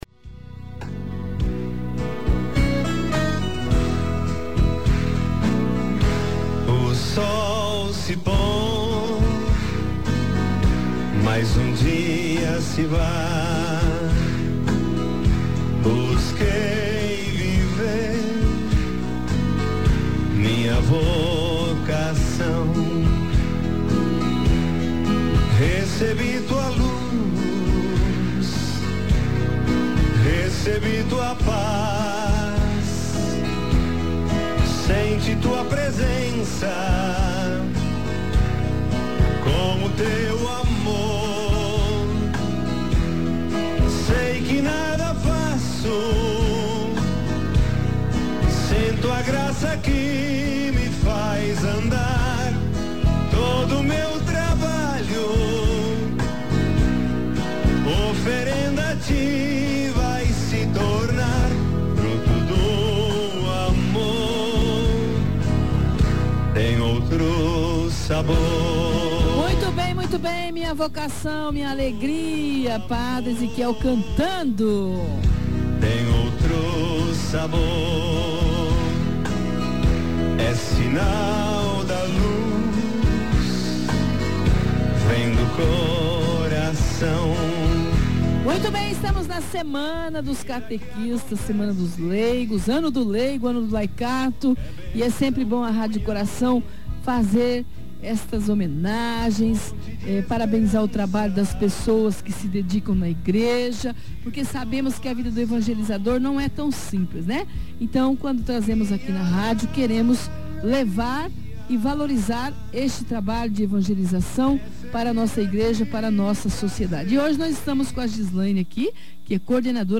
Semana dos catequistas: entrevista